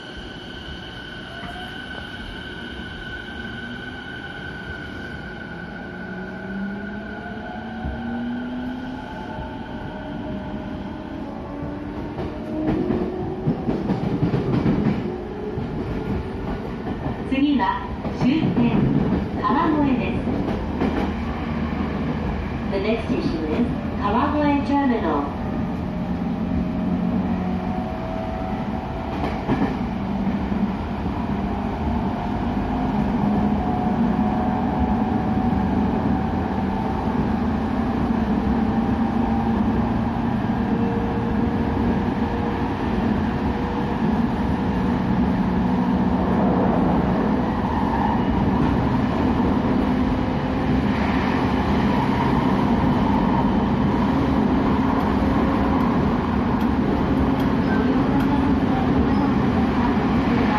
220829[走行音] E233川越線 南古谷→川越